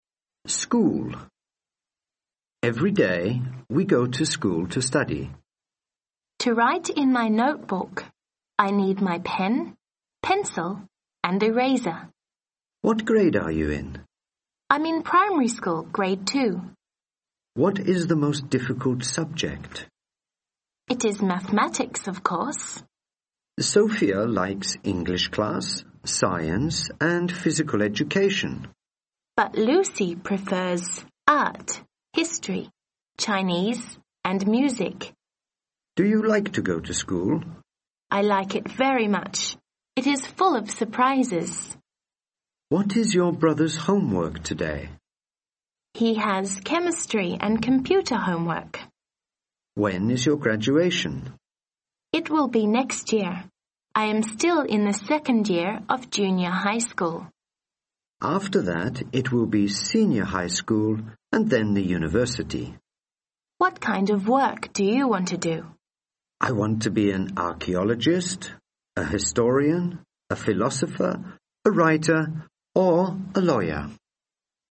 Dialogue：School